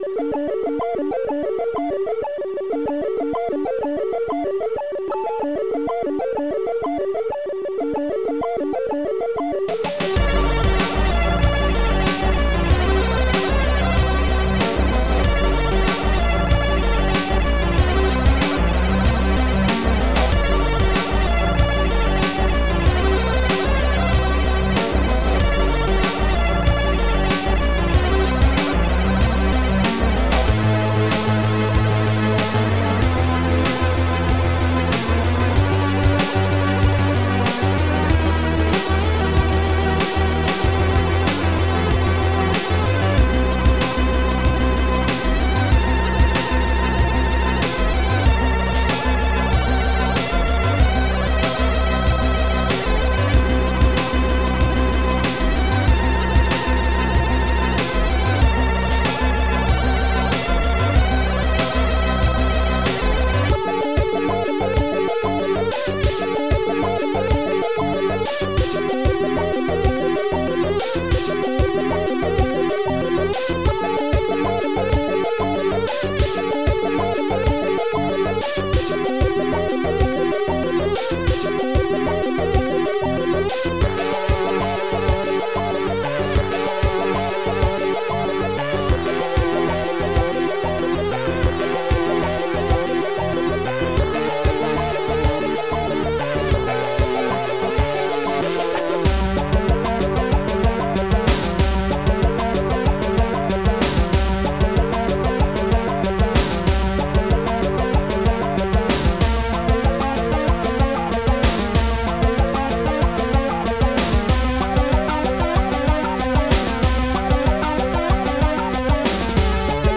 Protracker and family
Protracker M.K.
converted and remixed